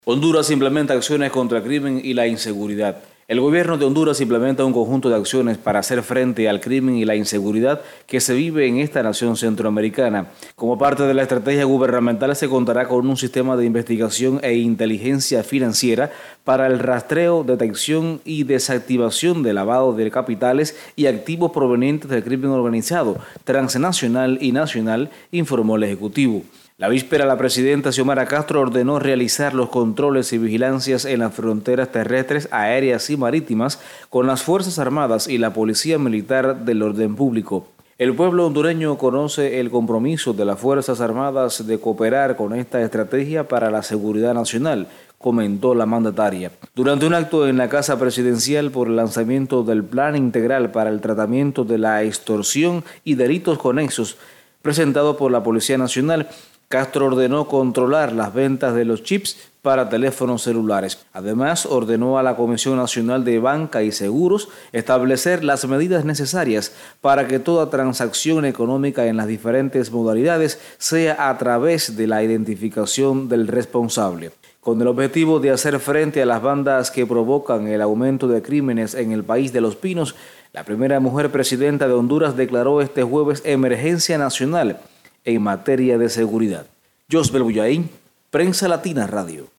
desde Tegucigalpa